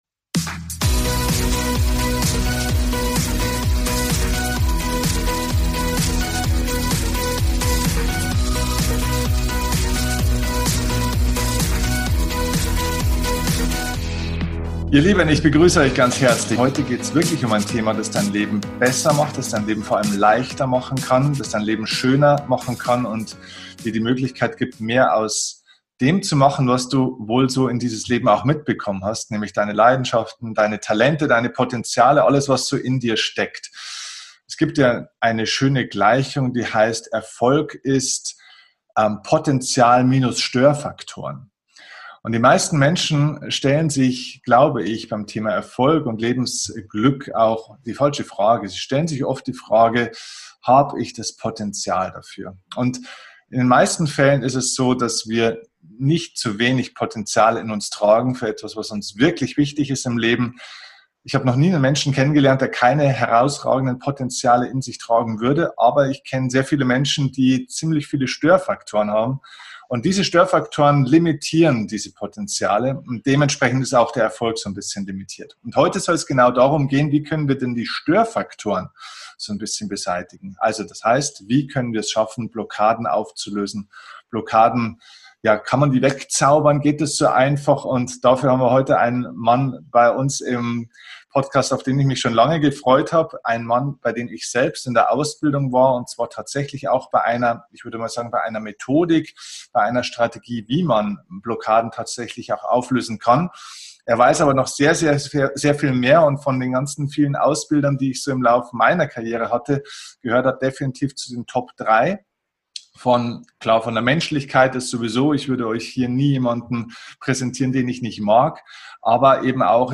#315 Wie Du innere Blockaden schnell auflösen kannst – Interview